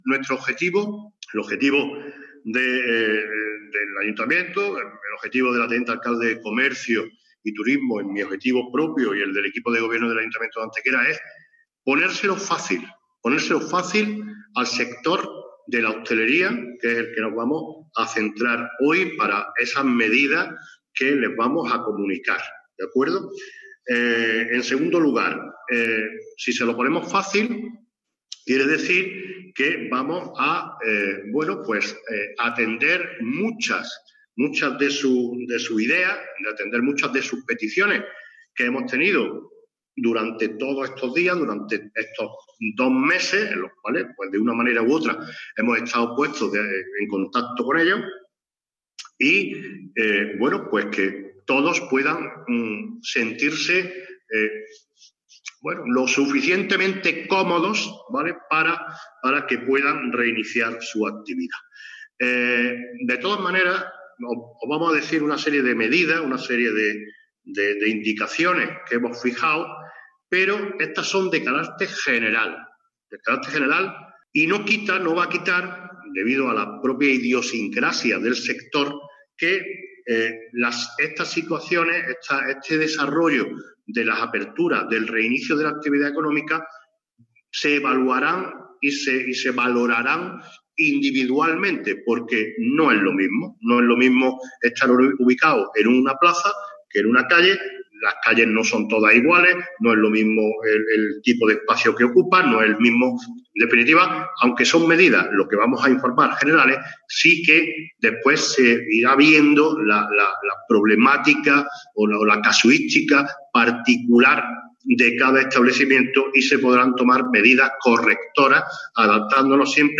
Mención especial durante la rueda de prensa ha requerido este último punto, para el que el Alcalde ha concretado que podría darse el caso de habilitar el cierre al tráfico de determinadas zonas o vías públicas para ampliar tanto el espacio dedicado a terrazas como el paso de peatones, facilitando así un mayor auge de la economía ligada a la hostelería y el tránsito seguro de personas.
Cortes de voz